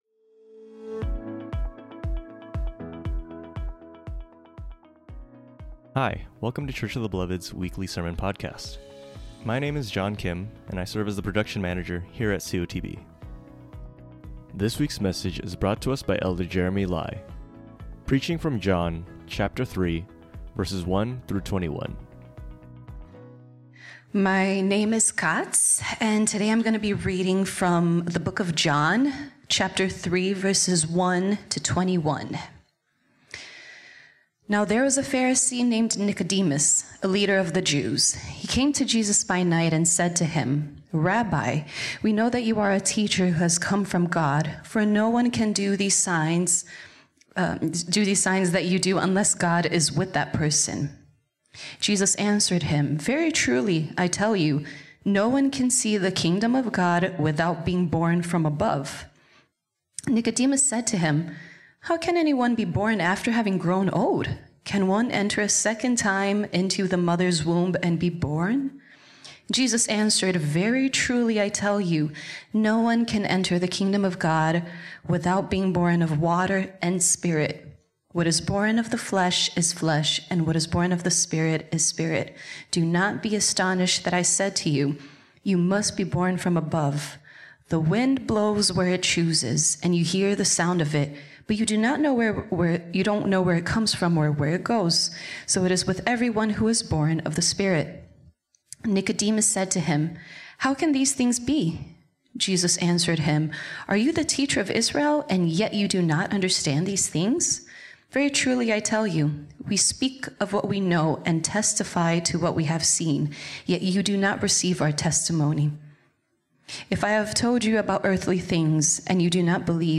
preaches from John 3:1-21